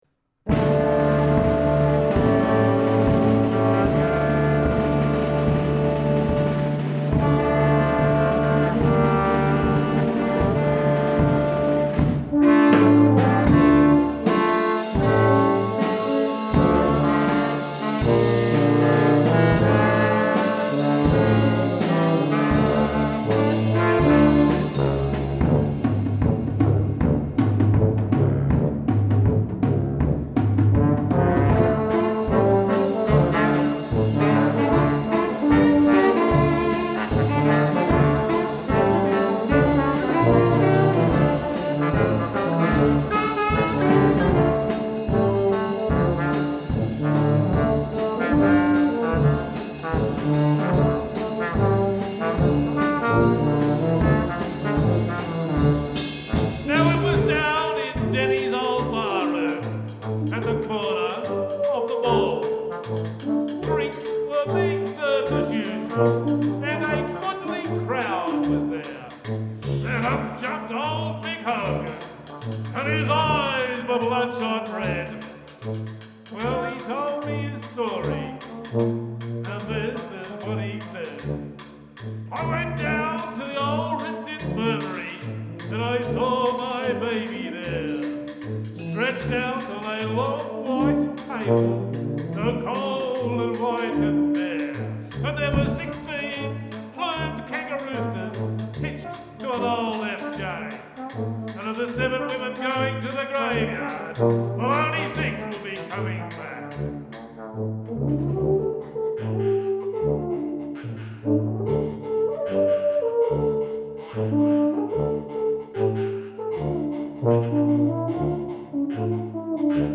Govett Street Stompers – Live
Our favourite five piece quartet
Dixieland